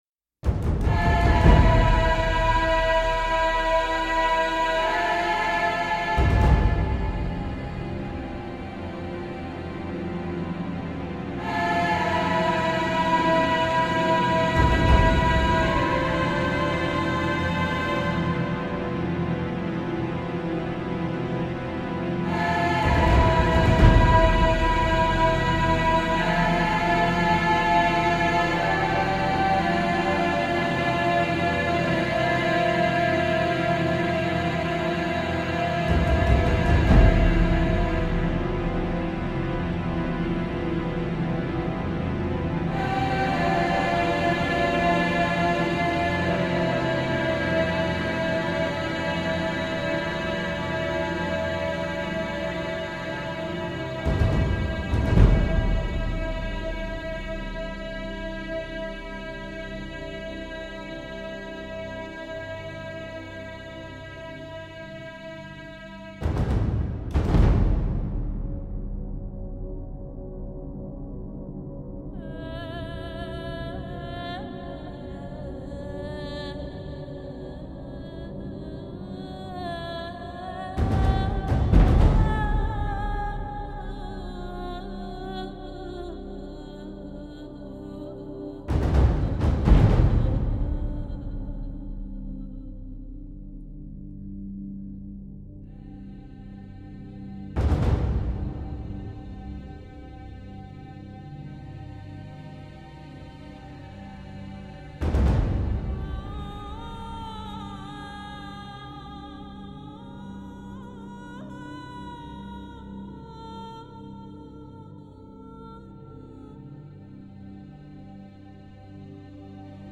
Нет точно музыка с фильма,ссори.